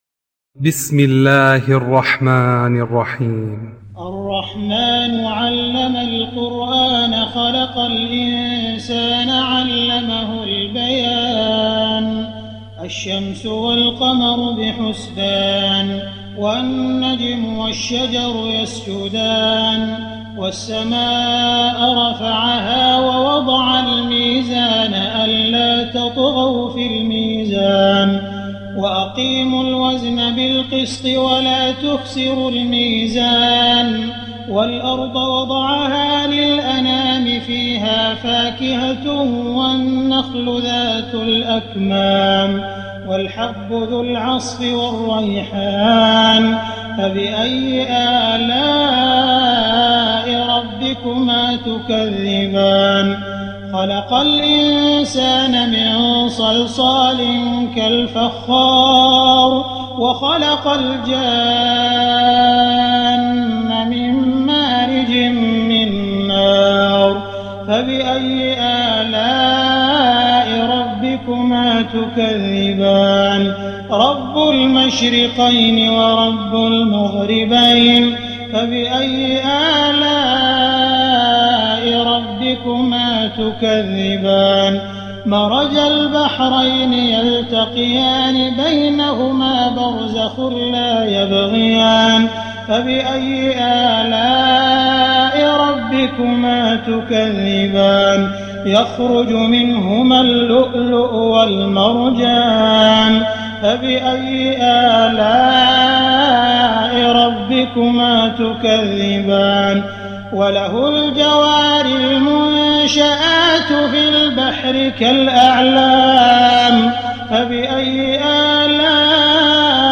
تراويح ليلة 26 رمضان 1419هـ من سور الرحمن الواقعة و الحديد Taraweeh 26 st night Ramadan 1419H from Surah Ar-Rahmaan and Al-Waaqia and Al-Hadid > تراويح الحرم المكي عام 1419 🕋 > التراويح - تلاوات الحرمين